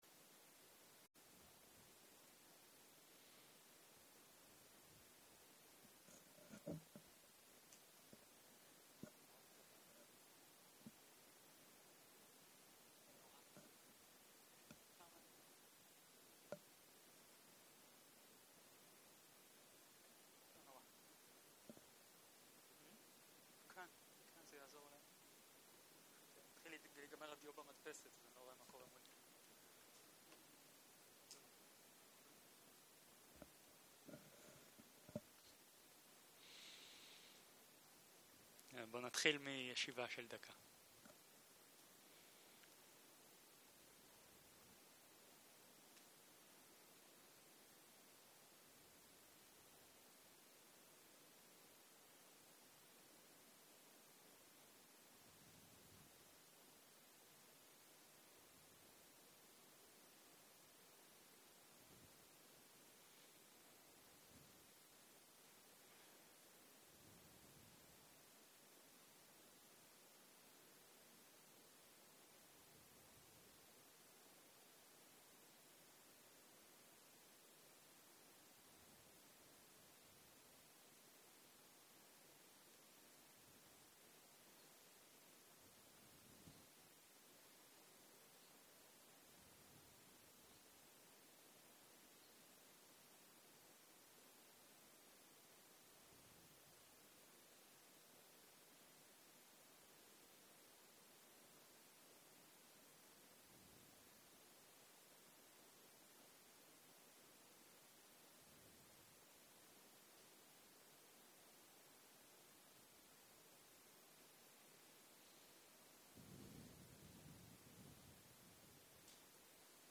סוג ההקלטה: שיחות דהרמה
ריטריט מטא וויפסנא של 10 ימים